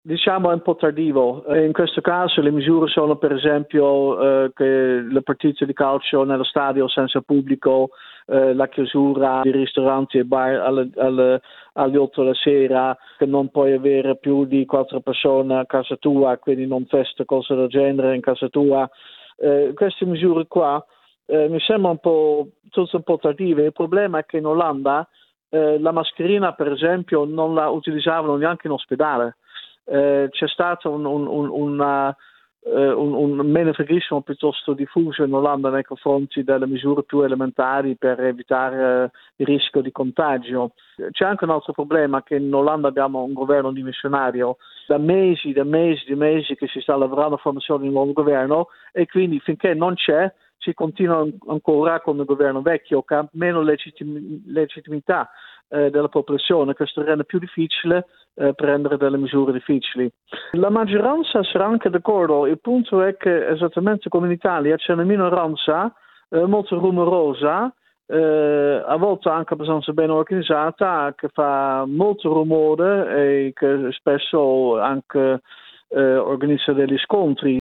Per capire meglio cosa sta succedendo nei Paesi Bassi e la natura di queste restrizioni, abbiamo intervistato il giornalista olandese